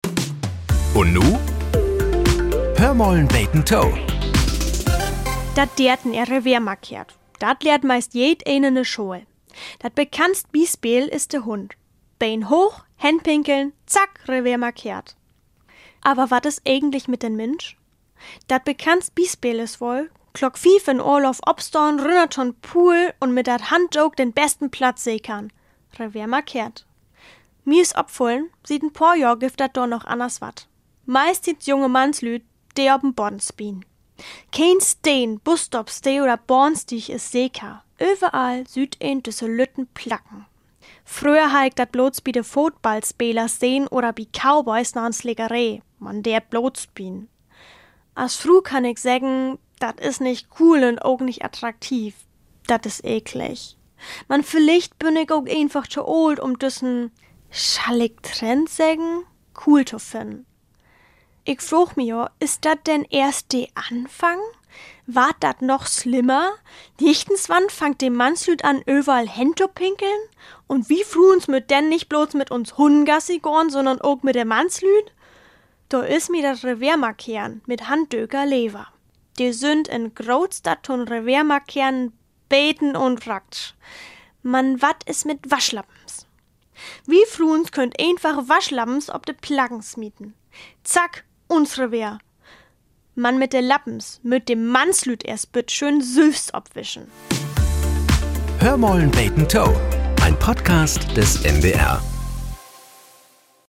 Nachrichten - 08.07.2025